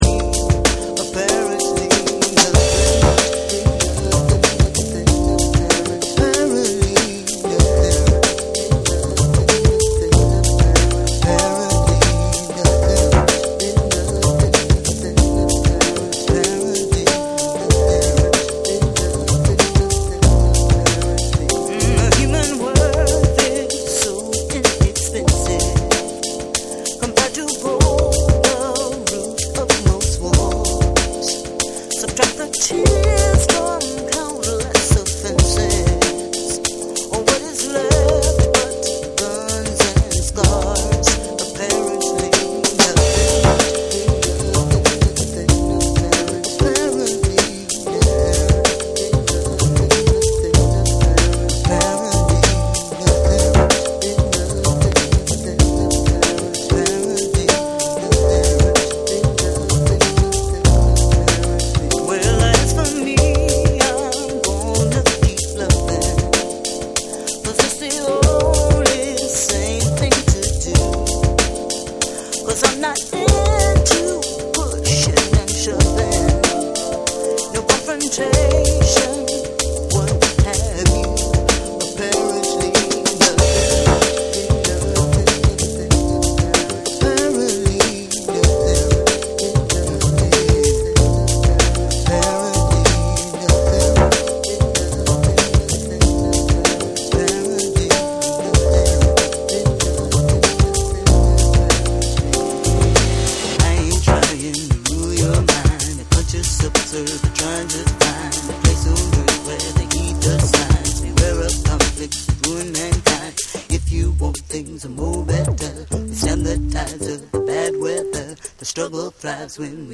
TECHNO & HOUSE / BREAKBEATS / SOUL & FUNK & JAZZ & etc